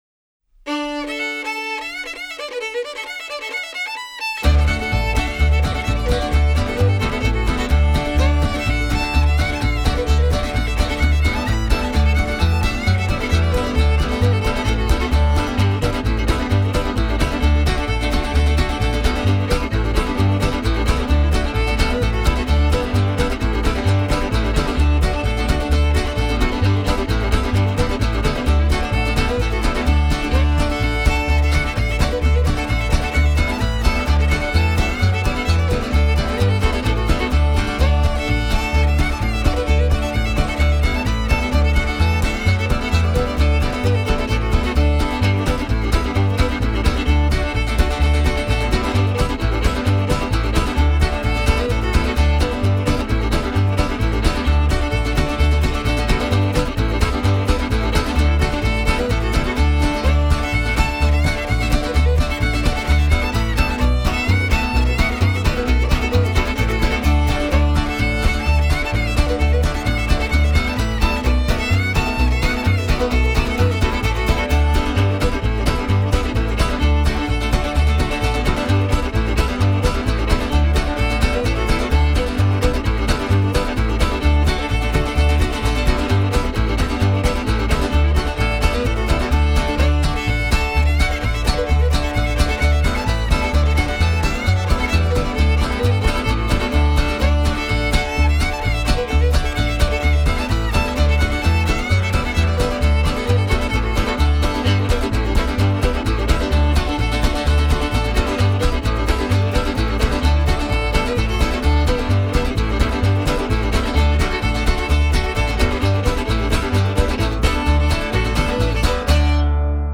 fiddle